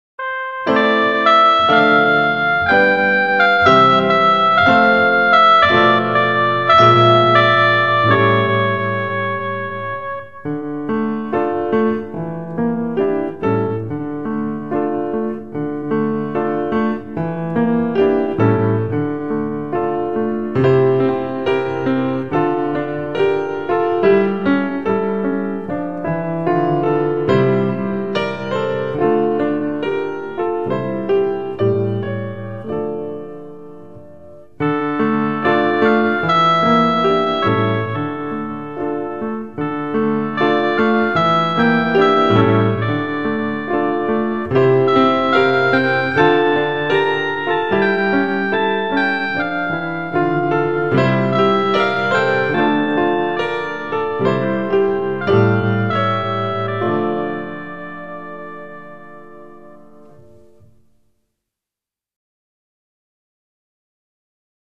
Audicions de flauta